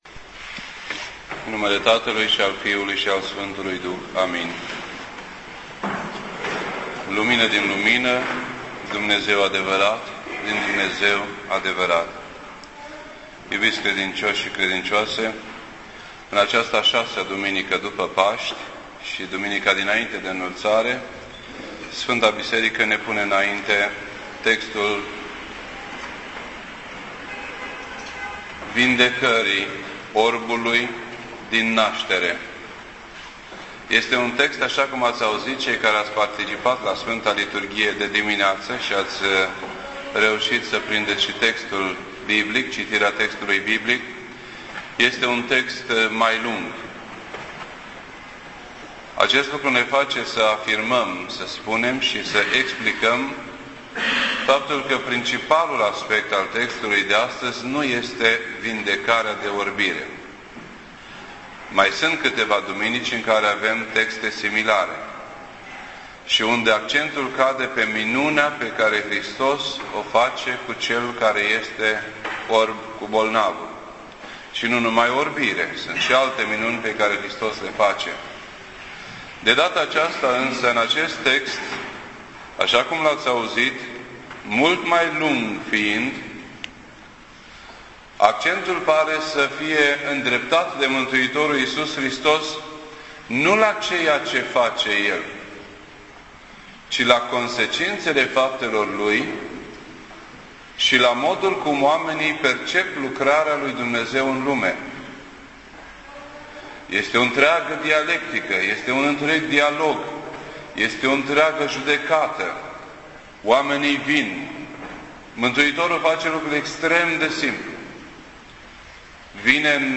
This entry was posted on Sunday, May 24th, 2009 at 6:49 PM and is filed under Predici ortodoxe in format audio.